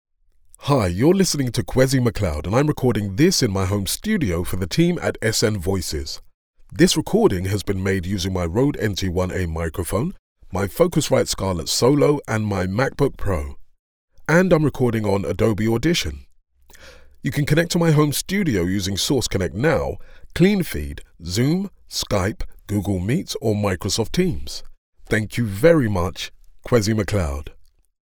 Smooth, Warm, Versatile, Resonant and Gravitas